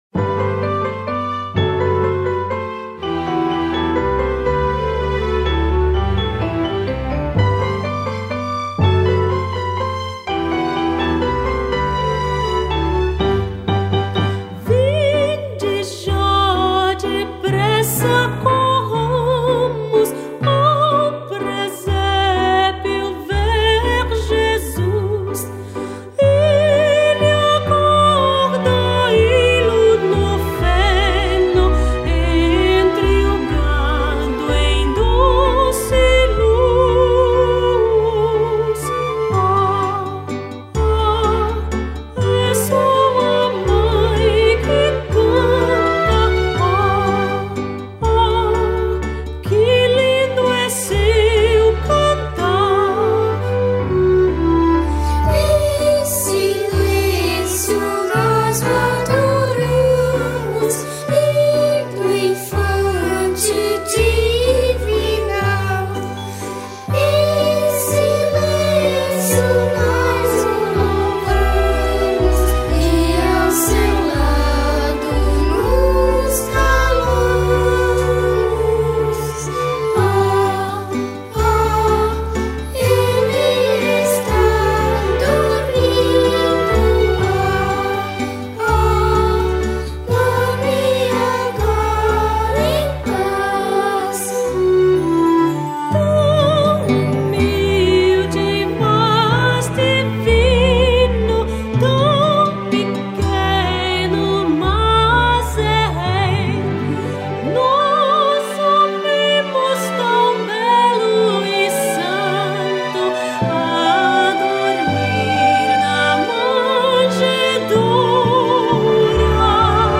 1049   03:05:00   Faixa:     Canção Religiosa